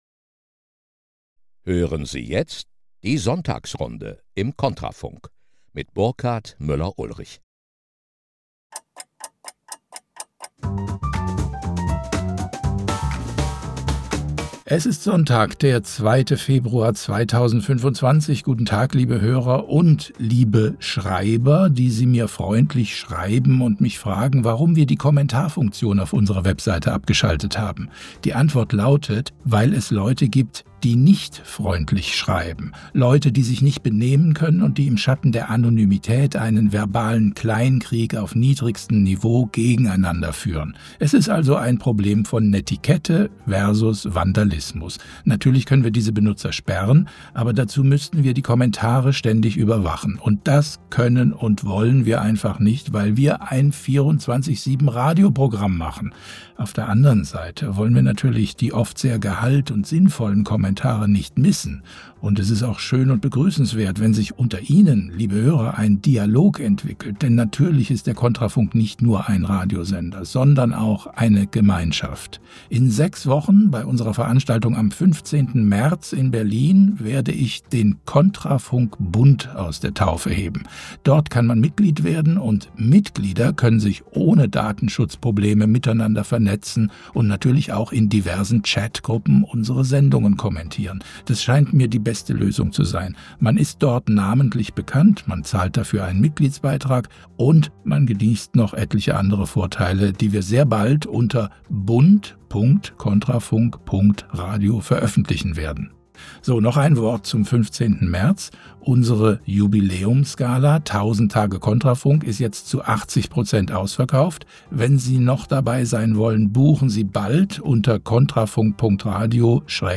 Im Mittelpunkt des Gesprächs stehen die Vorgänge der vergangenen Woche im Bundestag und bei den Vorfeldorganisationen der Kartellparteien.